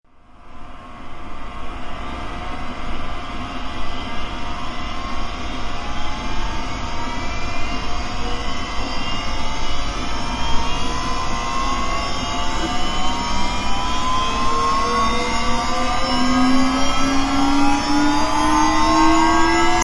Download Power Up sound effect for free.